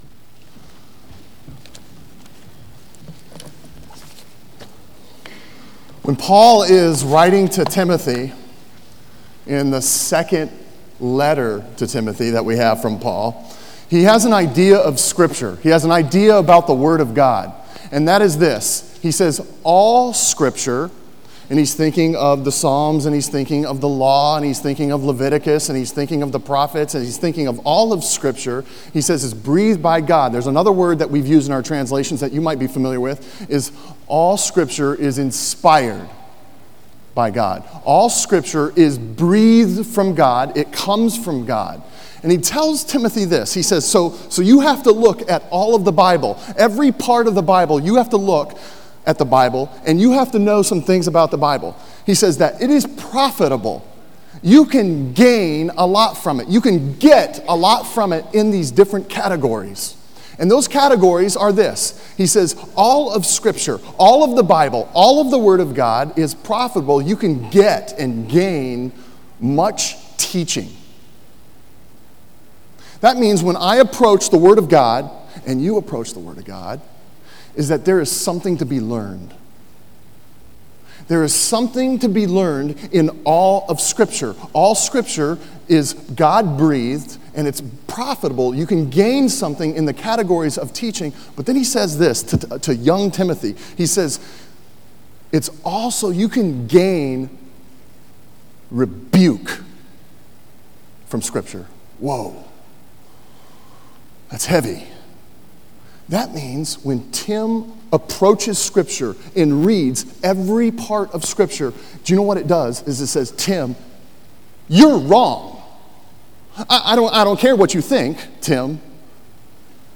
Sermon on Matthew 14:34-36 from May 26